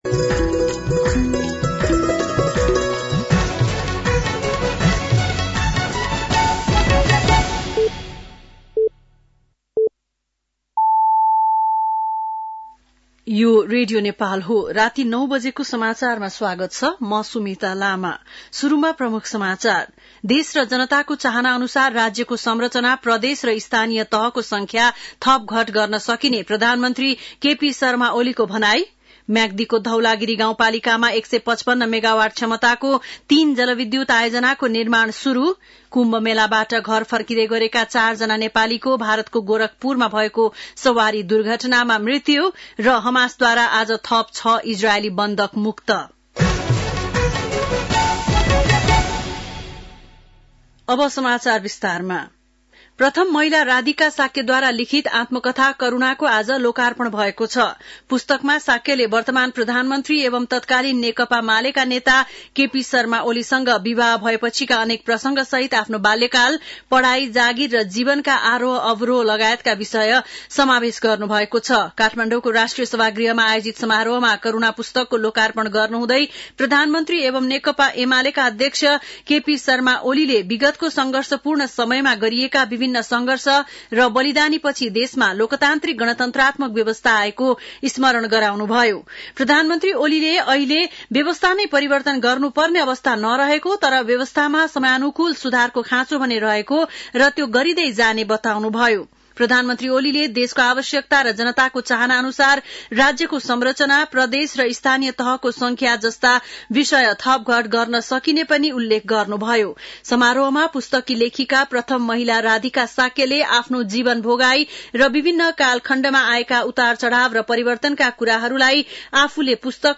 बेलुकी ९ बजेको नेपाली समाचार : ११ फागुन , २०८१